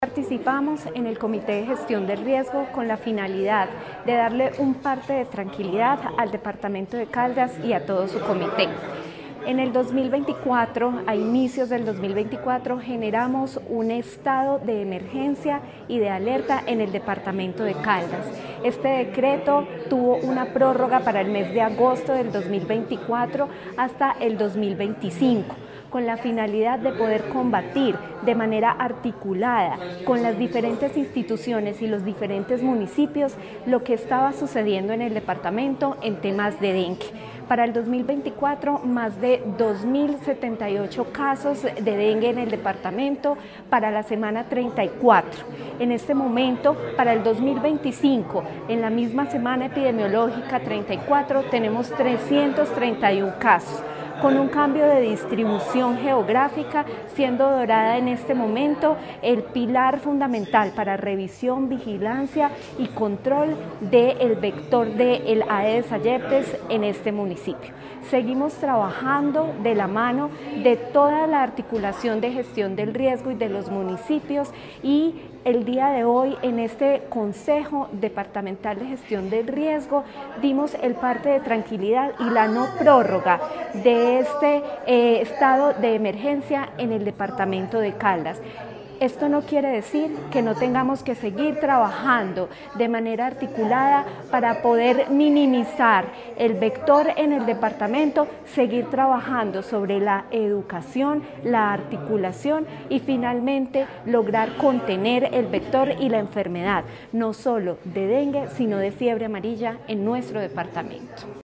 Durante el Comité Departamental de Gestión del Riesgo, la Directora de la Territorial de Salud de Caldas, Natalia Castaño Díaz, informó que no se continuará con la prórroga del estado de emergencia por dengue en el departamento, esto dada la disminución sostenida en los casos reportados.
Natalia Castaño Díaz, Directora de la DTSC.
AUDIO-NATALIA-CASTANO-DIAZ-DIRECTORA-DTSC.mp3